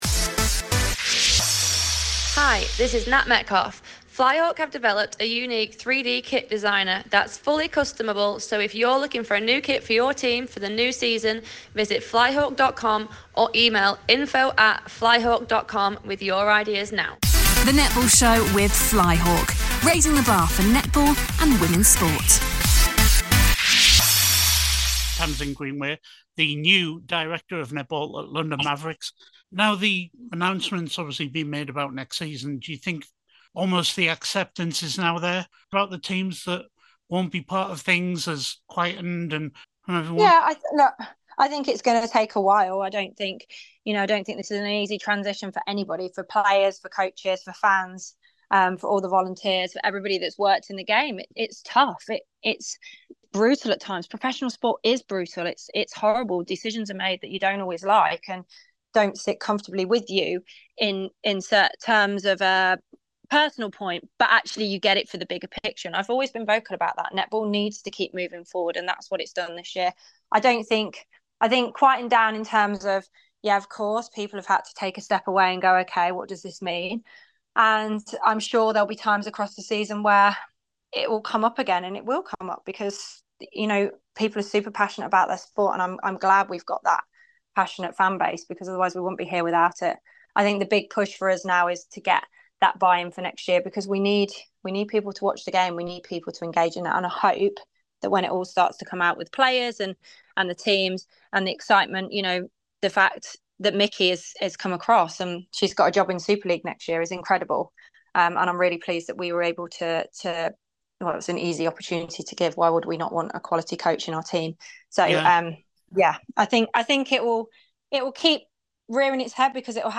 More from our recent chat with Tamsin Greenway looking at London Mavericks and the 2025 Netball Superleague